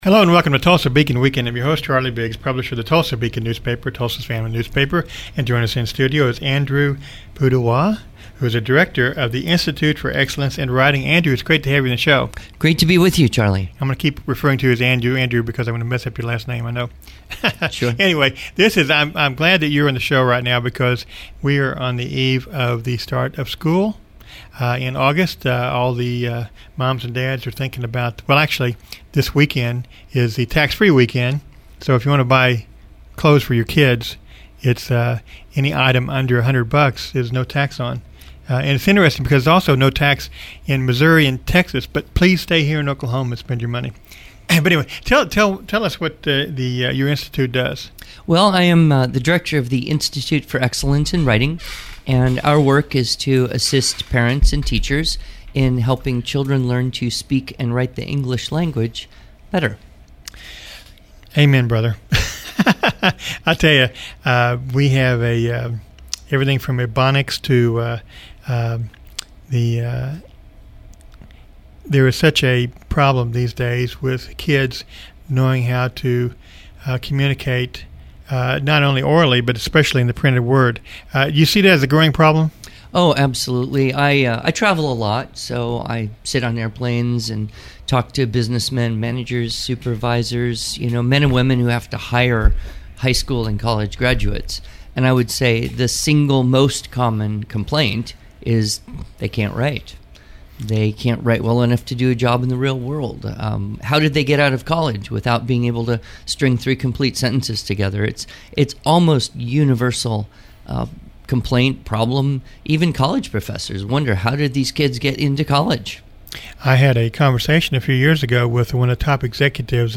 Listen to a radio interview